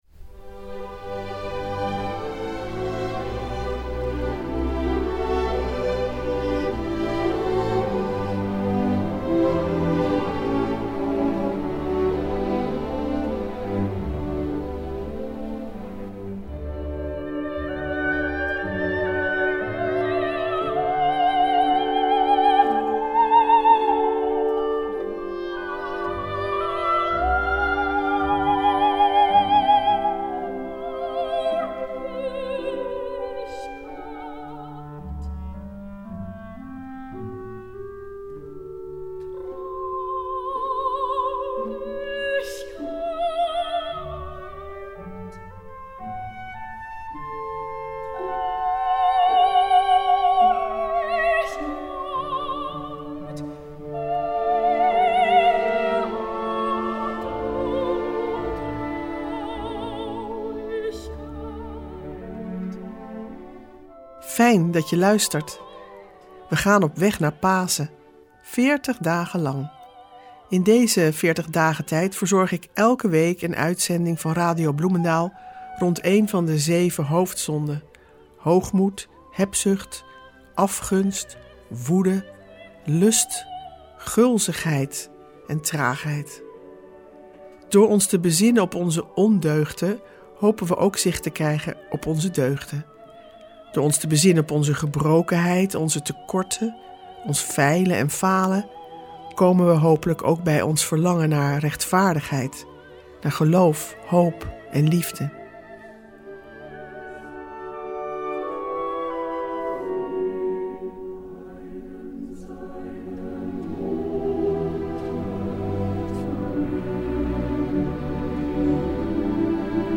Meditaties in de Veertigdagentijd